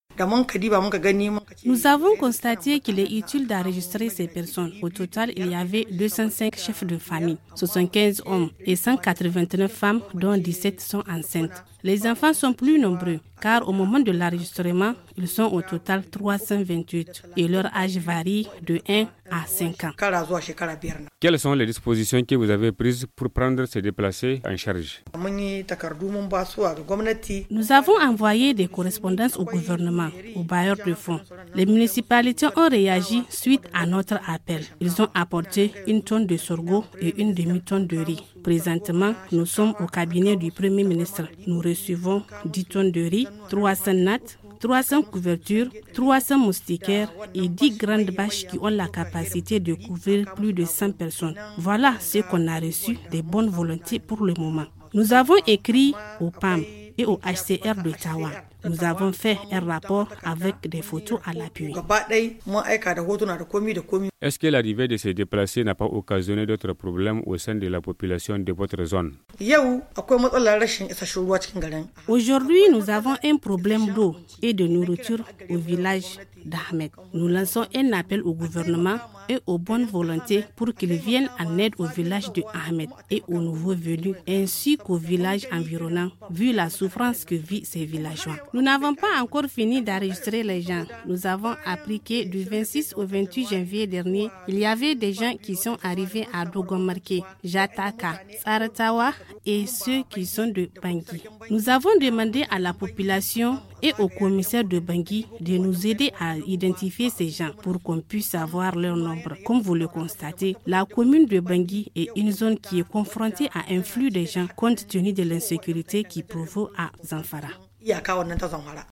Le magazine du 06/02/2019- A Bangui, département de Madaoua, madame le préfet de Madaoua s’exprime sur la prise en charge des centaines de réfugiés nigérians - Studio Kalangou - Au rythme du Niger